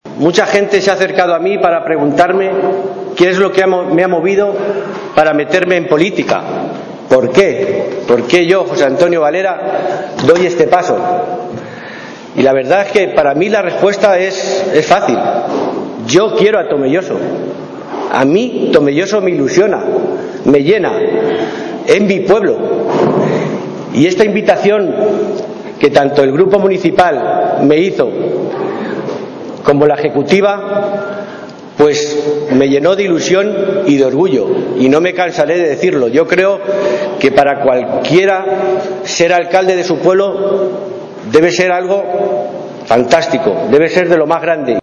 un acto en el que participaron cerca de 800 personas